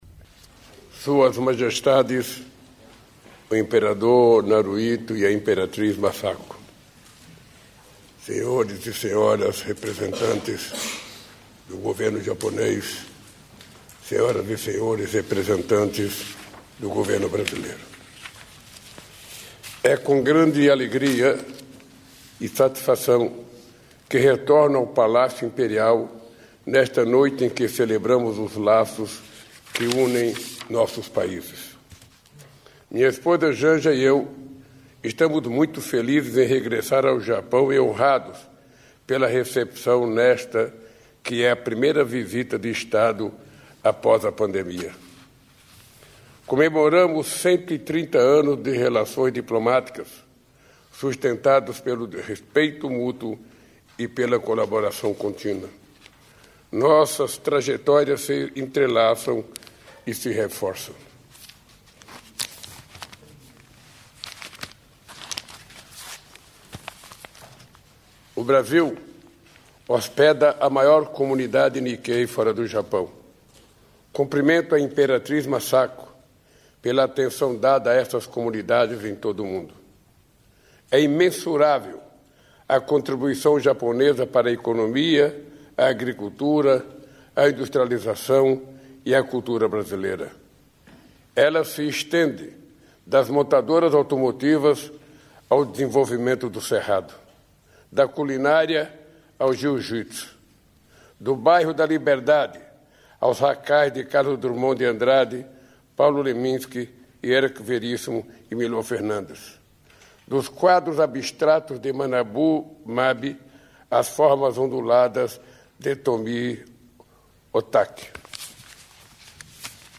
Íntegra do discurso do presidente Luiz Inácio Lula da Silva, em jantar oferecido pelo Imperador Naruhito e pela Imperatriz Masako, no Palácio Imperial em Tóquio (Japão), nesta terça-feira (25).